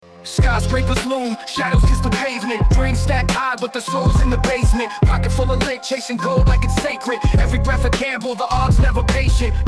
他一边用极快的语速演唱一首英文rap，一边摆着一个经典的、充满活力的说唱歌手姿势。
视频的音频部分完全由他的rap构成，没有其他对话或杂音。
rap.mp3